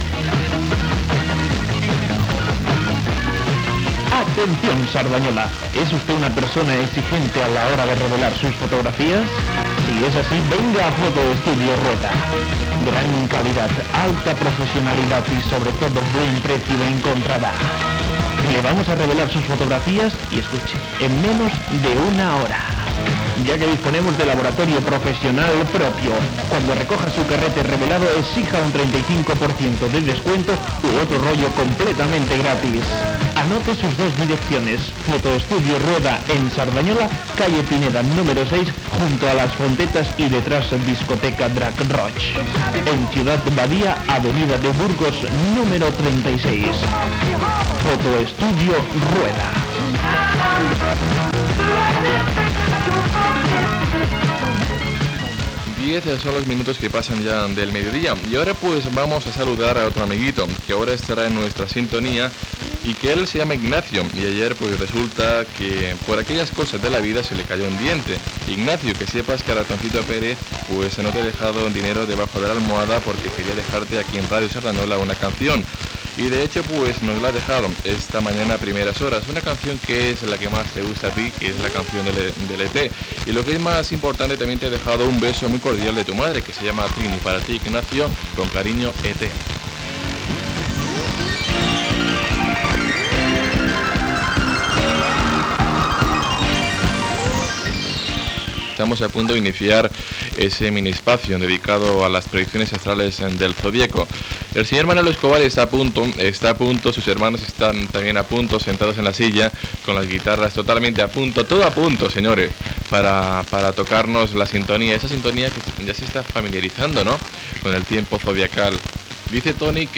7a89e65735cfe61f84dcc5c740d53e6f817481a5.mp3 Títol Ràdio Cerdanyola Emissora Ràdio Cerdanyola Titularitat Tercer sector Tercer sector Comercial Descripció Publicitat, disc dedicat i el zodíac.